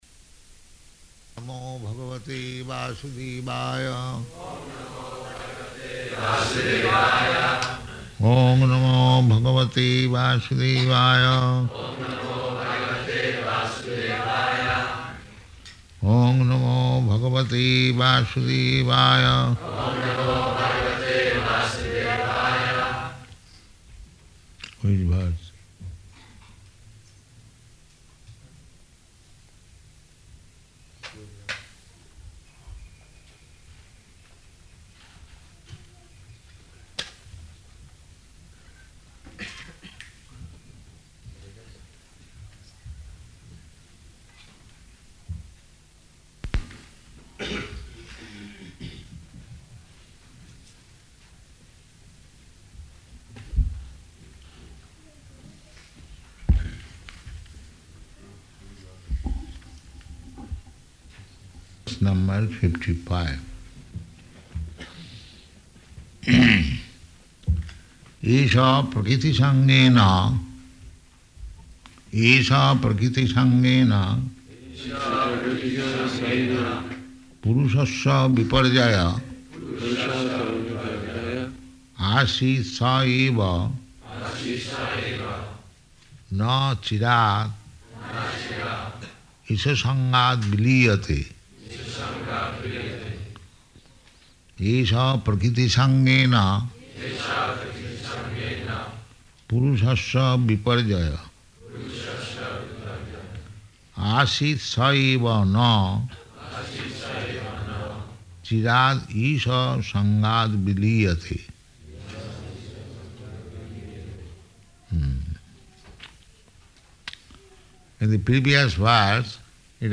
Śrīmad-Bhāgavatam 6.1.55 --:-- --:-- Type: Srimad-Bhagavatam Dated: August 11th 1975 Location: Paris Audio file: 750811SB.PAR.mp3 [devotee translates into French throughout] Prabhupāda: Oṁ namo bhagavate vāsudevāya.
[leads chanting line by line] eṣa prakṛti-saṅgena puruṣasya viparyayaḥ āsīt sa eva na cirād īśa-saṅgād vilīyate [ SB 6.1.55 ] Hmm.